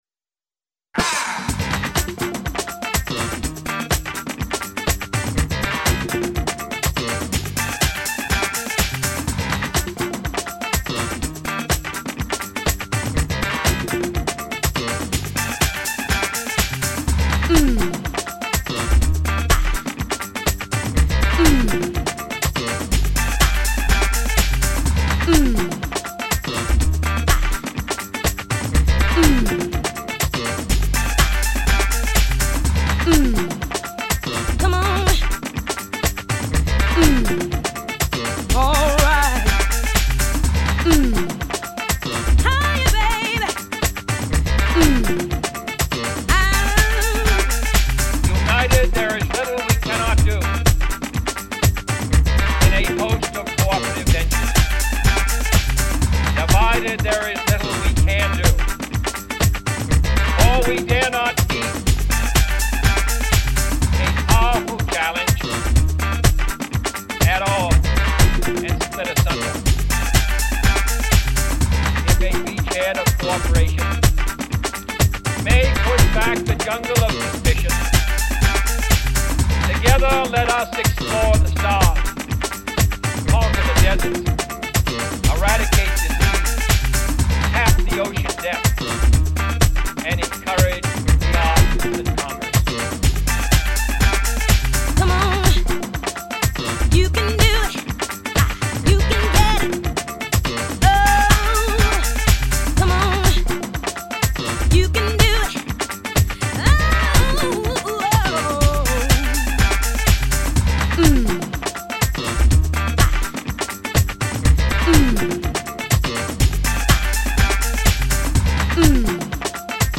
My first re-mix.